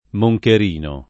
moncherino [ mo j ker & no ] s. m.